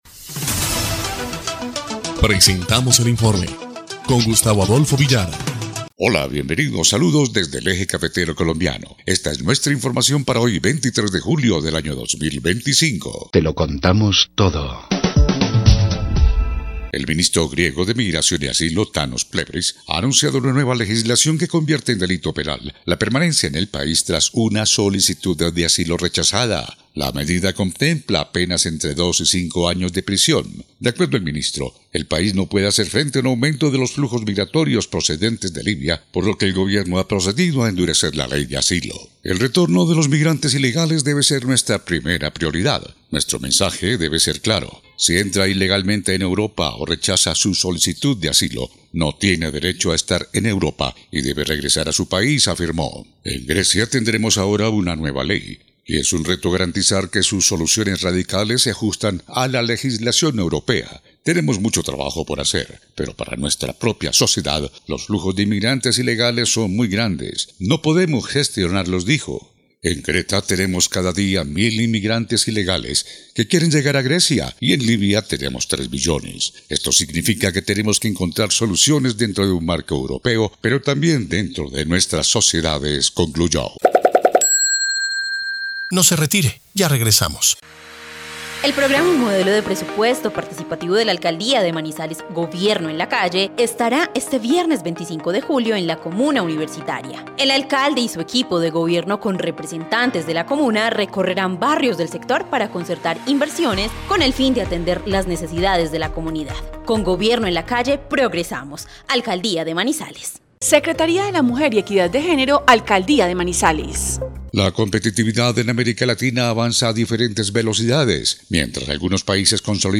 EL INFORME 2° Clip de Noticias del 23 de julio de 2025